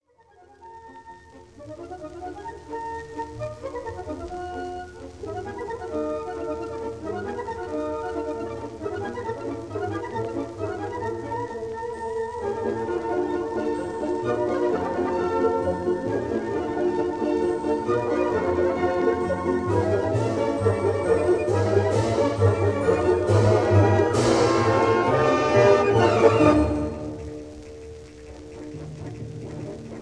Recorded in Teatro alla Scala, Milan on 19 July 1947